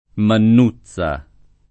Mannuzza [ mann 2ZZ a ] → Mannutza